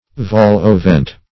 Vol-au-vent \Vol`-au`-vent"\, n. [F.] (Cookery)